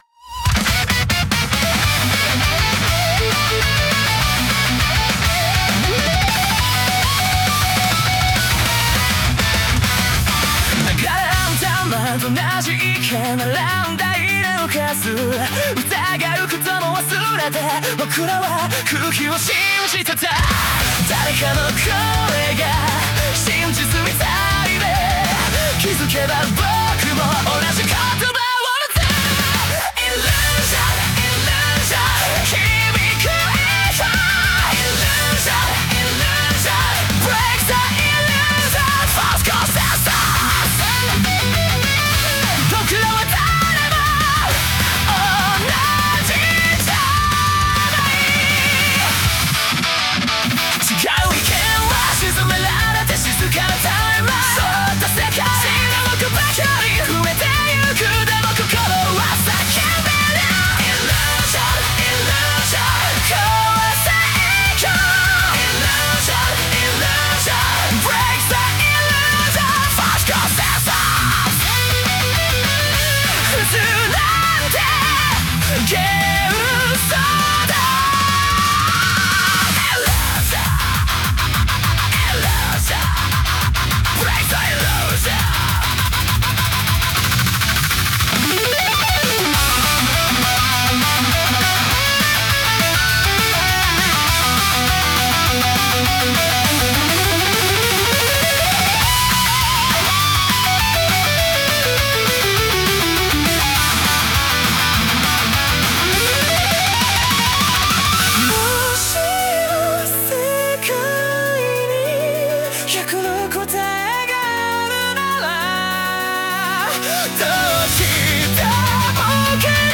歌ものフリー素材 bgm音楽 無料ダウンロード 商用・非商用ともに登録不要で安心してご利用いただけます。
男性ボーカル